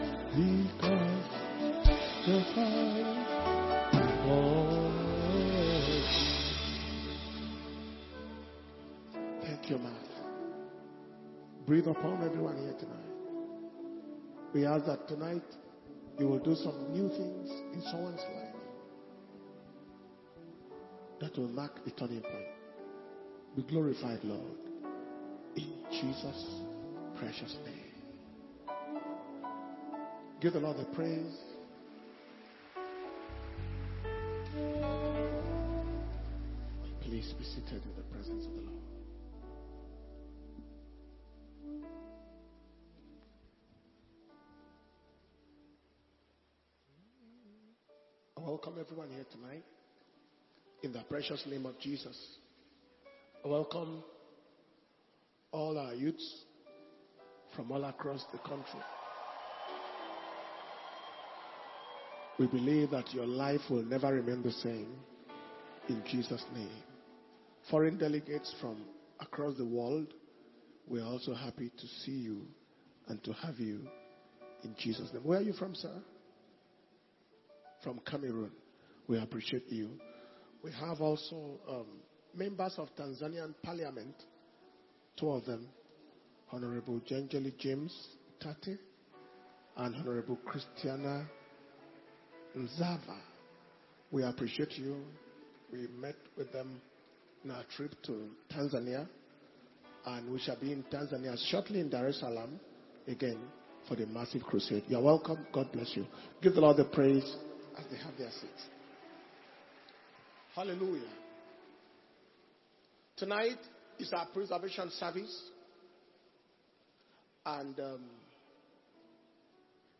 August 2023 Preservation/Power Communion Service.